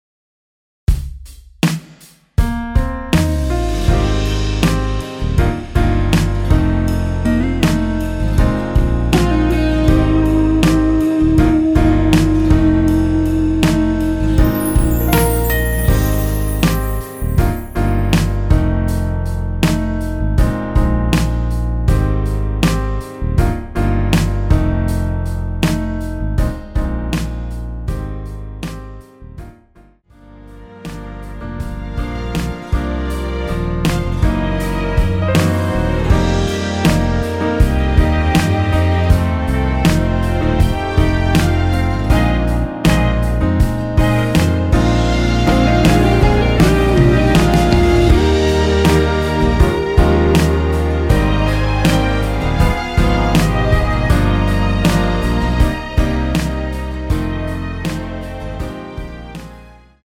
엔딩이 페이드 아웃이라 라이브 하시기 좋게 엔딩을 만들어 놓았습니다.
Eb
앞부분30초, 뒷부분30초씩 편집해서 올려 드리고 있습니다.
중간에 음이 끈어지고 다시 나오는 이유는